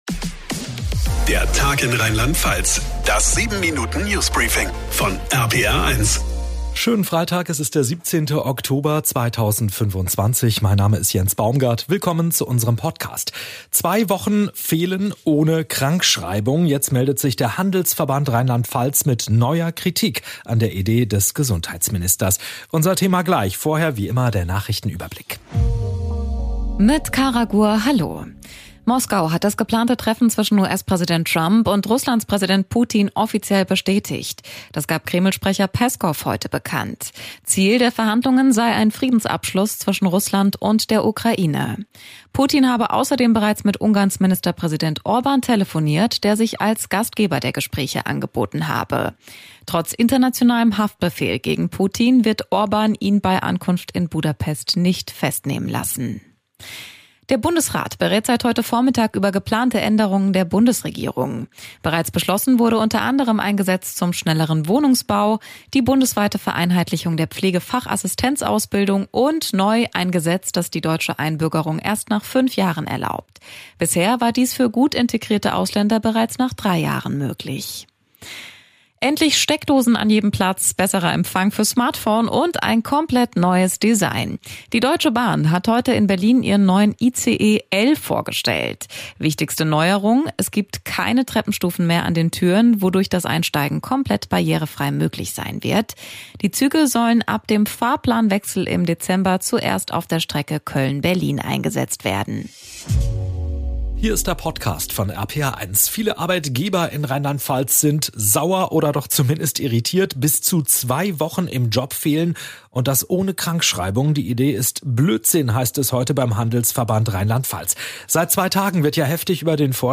Das 7-Minuten News Briefing von RPR1.